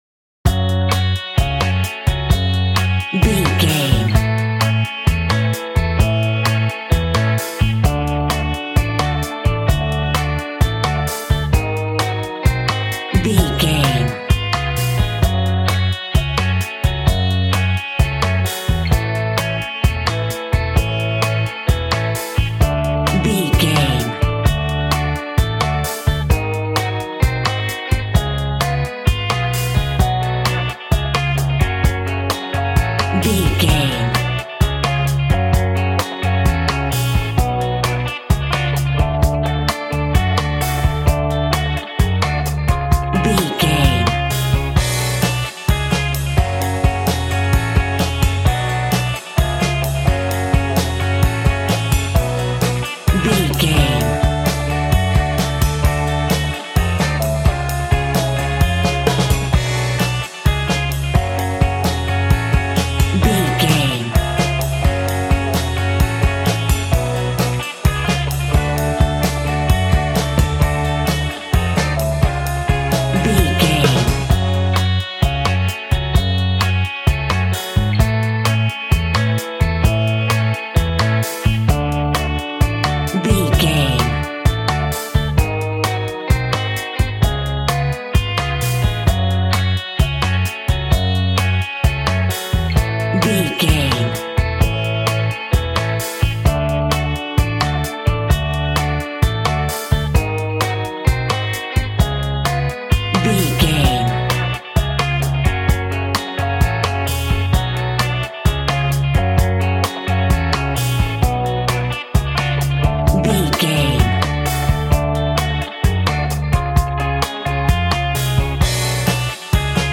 Aeolian/Minor
G♭
uplifting
bass guitar
electric guitar
drums
cheerful/happy